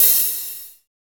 HARD OHH.wav